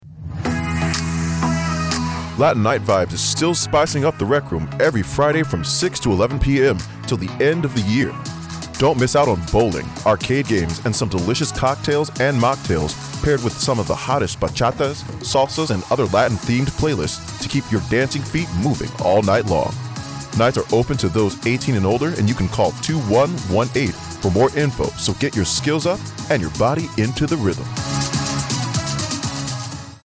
A radio spot informing Naval Station Guantanamo Bay residents of Latin Night Vibes.